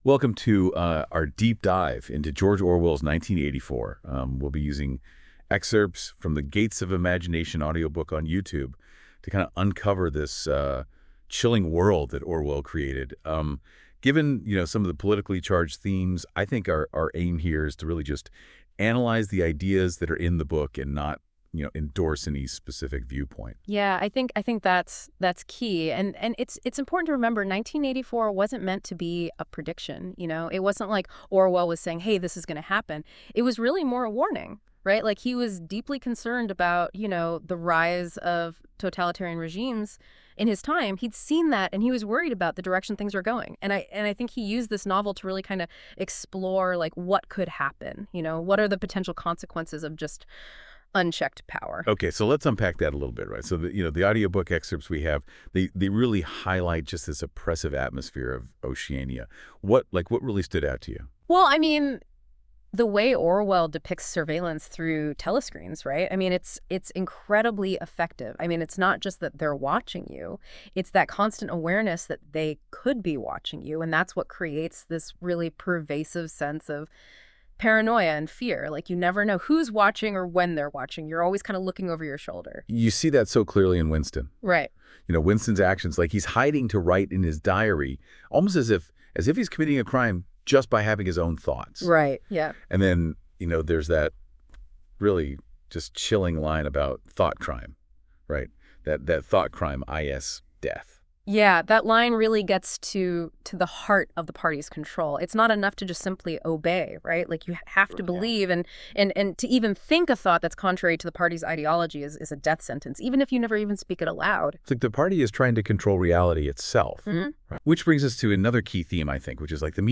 Nineteen Eighty-Four: A Conversation On 1984 plus a quick study guide for the book